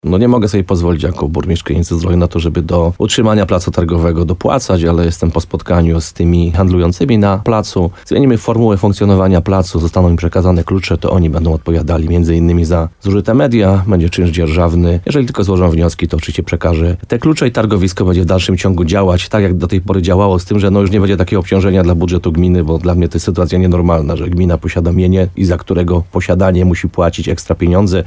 Burmistrz Piotr Ryba mówił w programie Słowo za słowo w radiu RDN Nowy Sącz, że liczba handlujących jest bardzo mała, a utrzymanie placu przynosiło dużo większe koszty niż dochody z opłaty targowej.